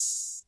Open Hats
Metro_open.wav